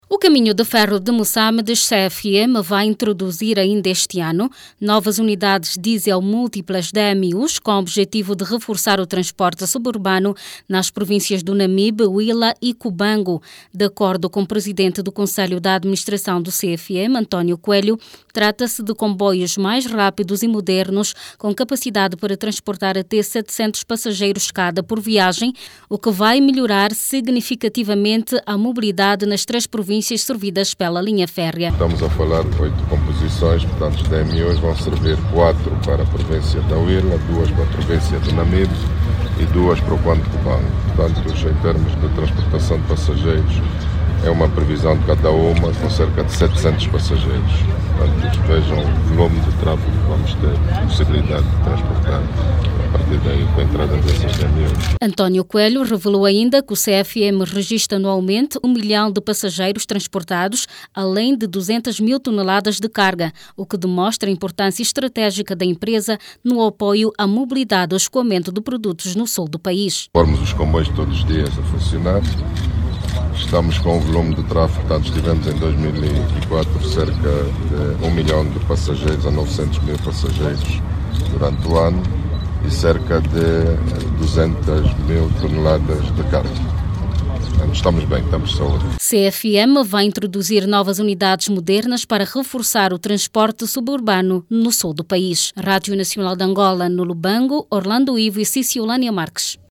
O Caninhos-de-Ferro de Moçâmedes, pretende modernizar os seus serviços com a introdução de novas unidades diesel responsáveis por injectar o combustível com precisão na câmara de combustão com uma capacidade para transportar 7.00 passageiros cada por viagens. Clique no áudio abaixo e ouça a reportagem